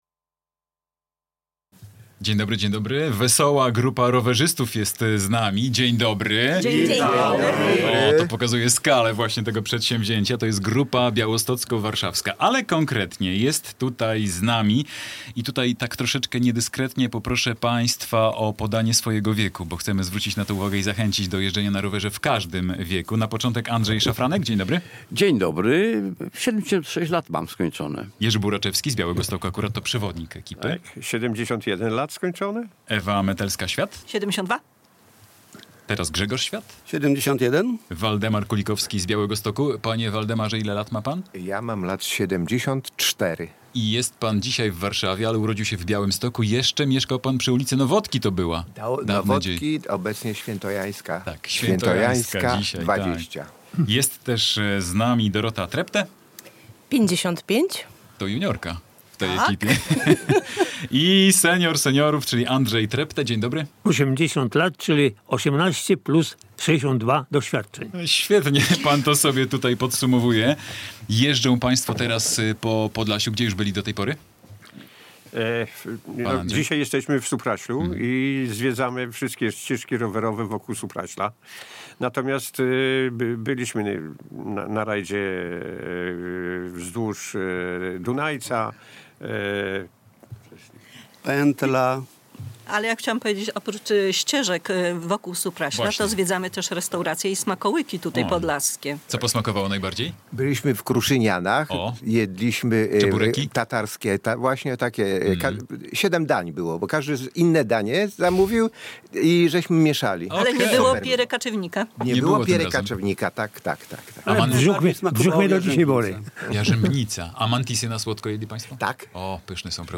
Z rowerzystami rozmawia